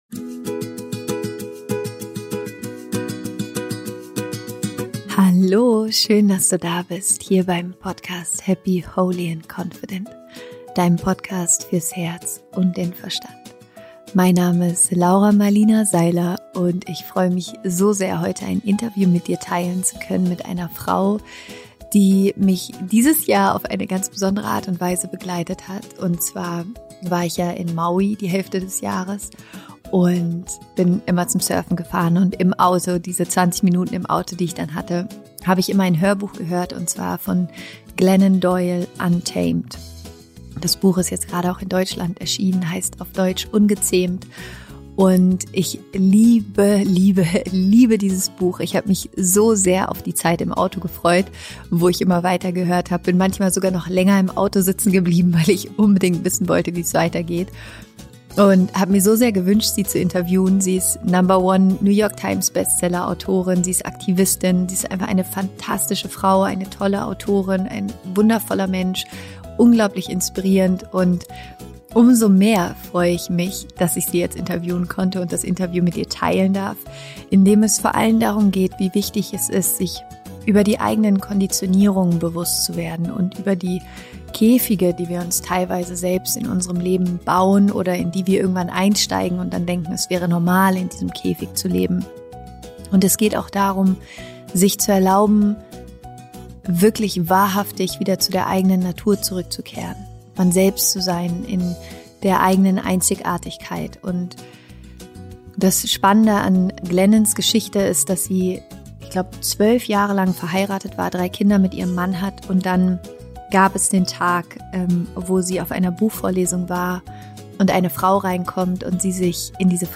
Befreie dich von Erwartungen – Interview Special mit Glennon Doyle